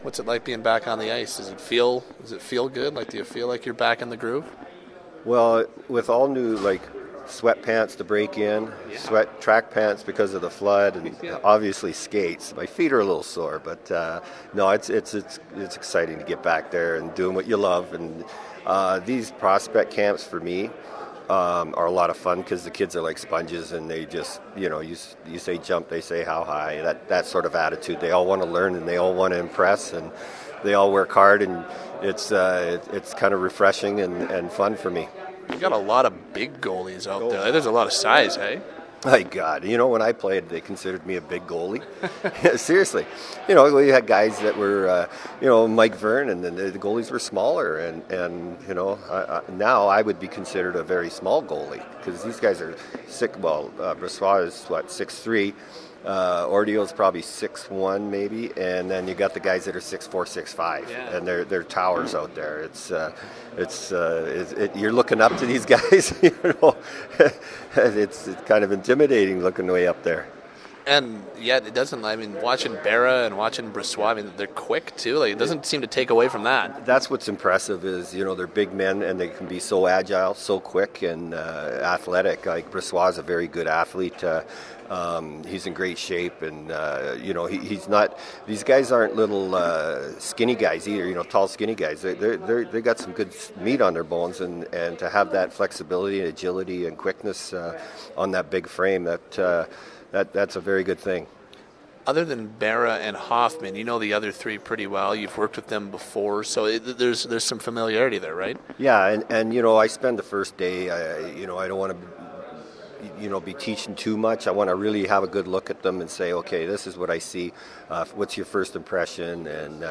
Malarchuk interview; he likes his big goalies